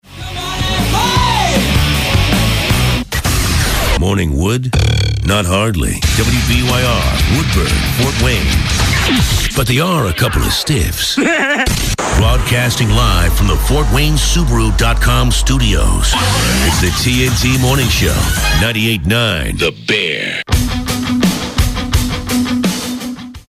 WBYR Top of the Hour Audio: